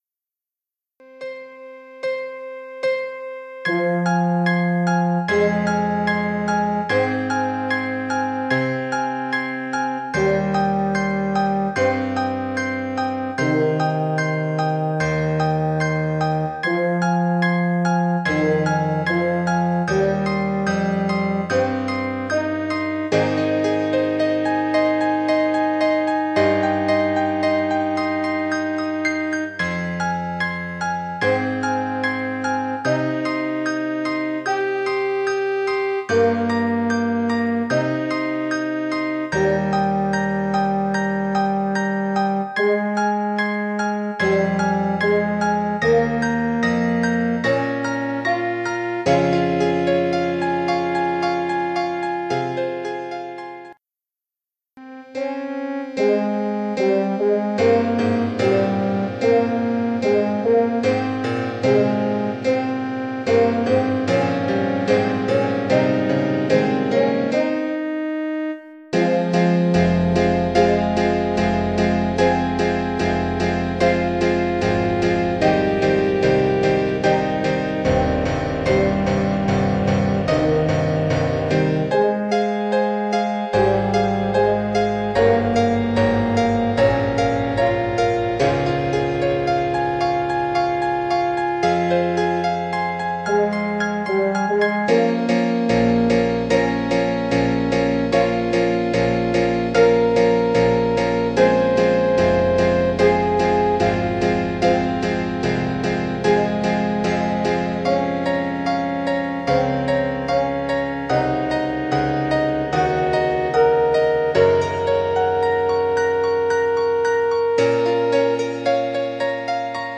5 Stücke für Horn und Klavier
Besetzung: Horn in F, Klavier
5 Pieces for Horn and Piano
Instrumentation: horn in f, piano